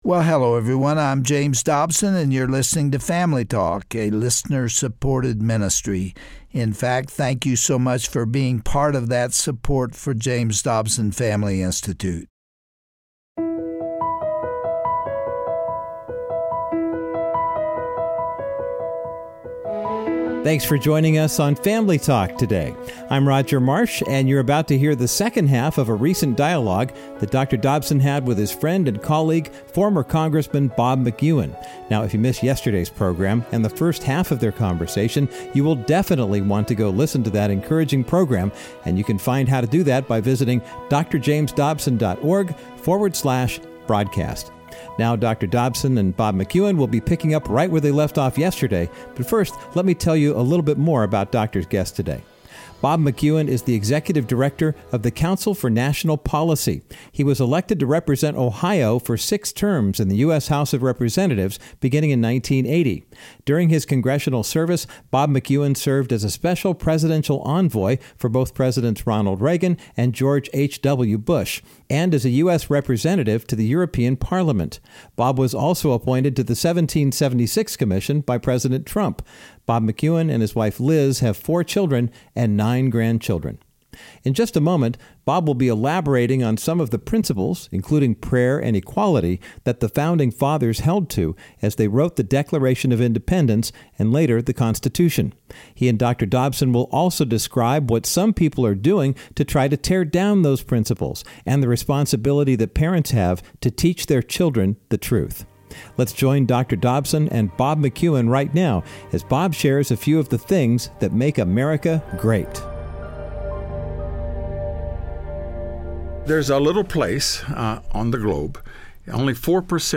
On today's Family Talk, former U.S. Congressman Bob McEwen joins Dr. Dobson in-studio to discuss what is right about America today.